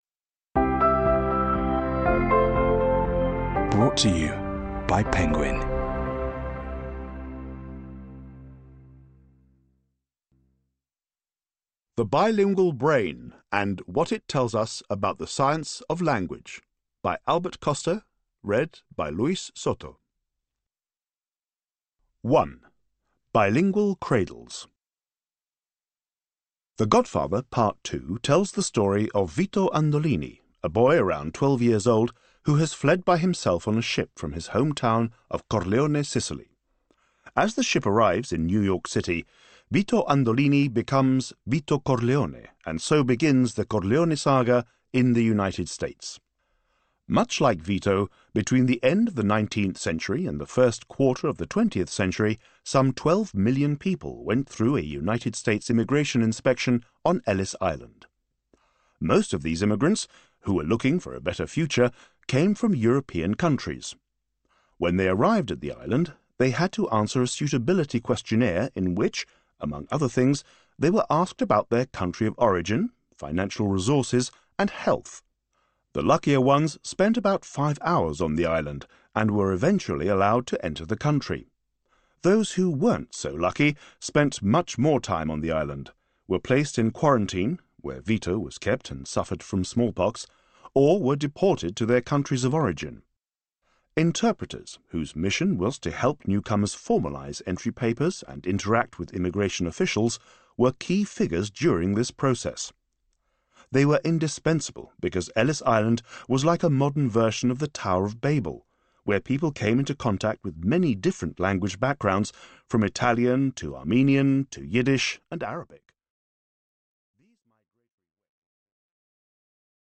Audiobook - The Bilingual Brain